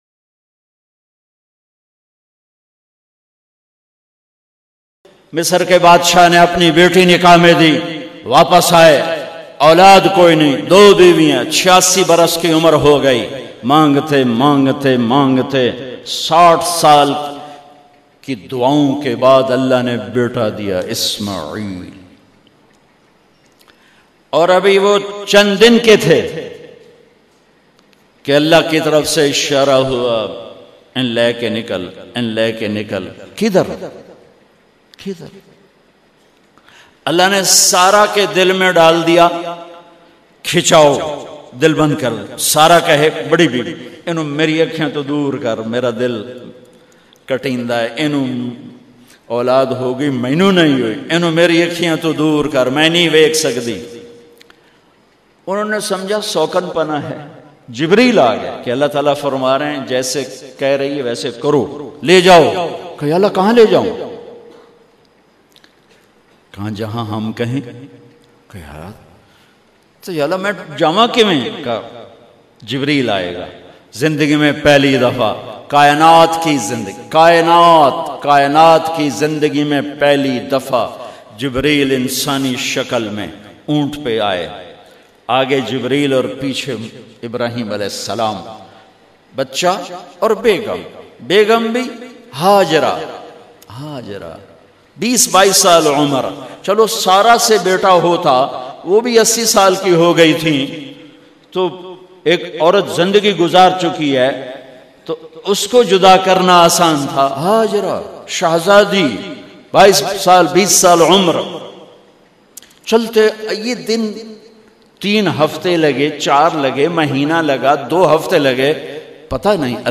Tariq Jameel, commonly referred to as Maulana Tariq Jameel, is a Pakistani religious and Islamic scholar, preacher, and public speaker from Tulamba near Mian Channu in Khanewal, Punjab in Faisalabad, Pakistan.